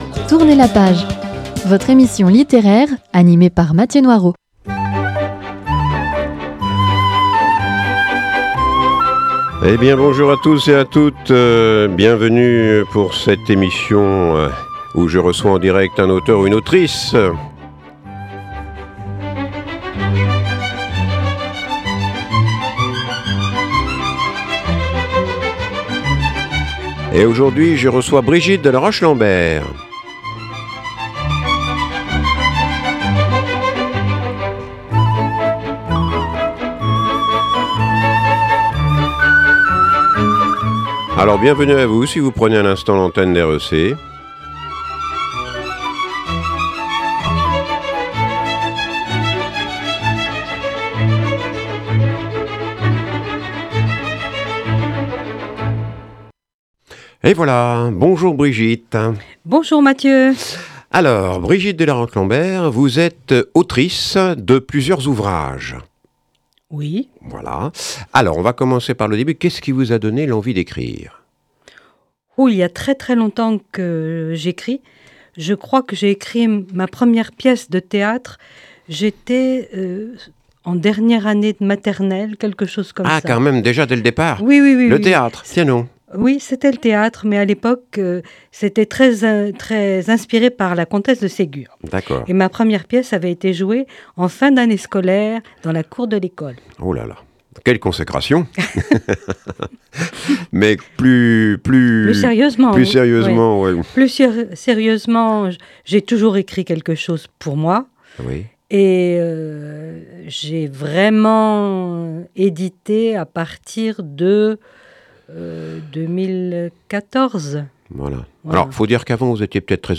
Émission littéraire avec un·e invité·e : auteur ou autrice qui nous parle de son métier, de ses ouvrages ou de son dernier livre édité.